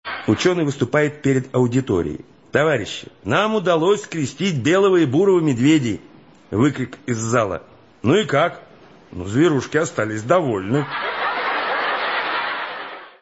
Звуки анекдотов